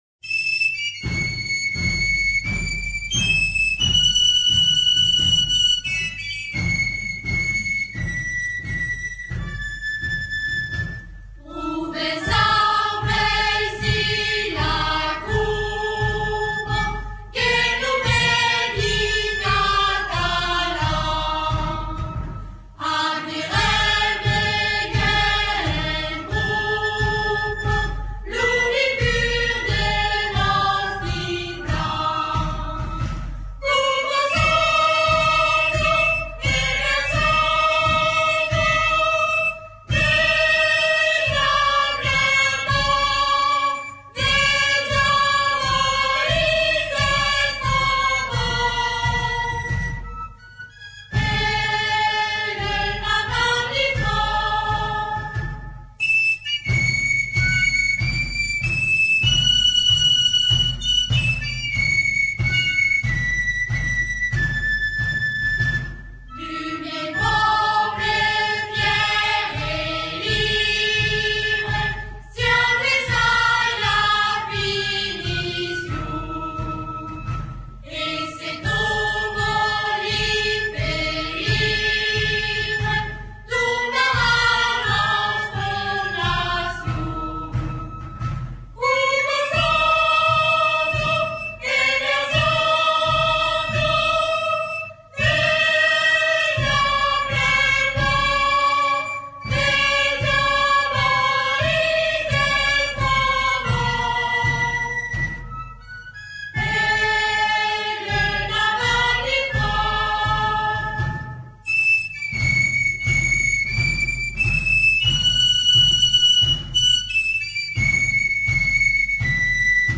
Version groupe folklorique (chorale + galoubets-tambourins)